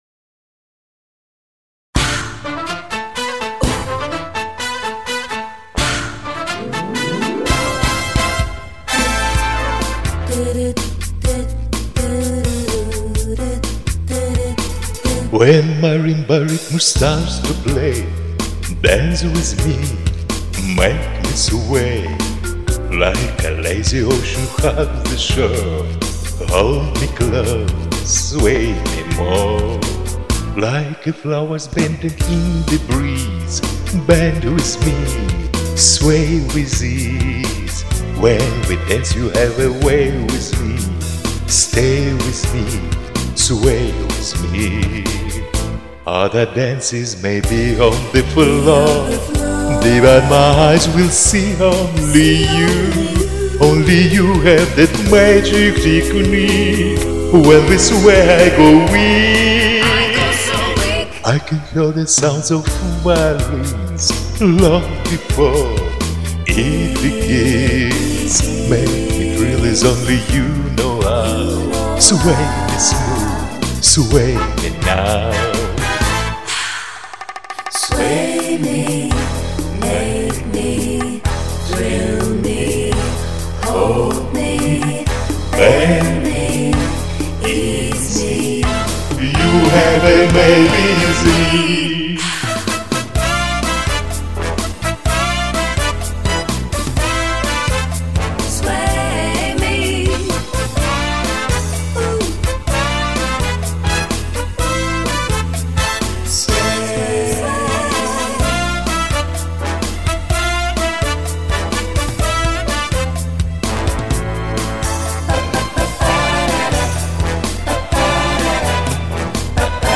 В общем, у нас с оттенком "латинос".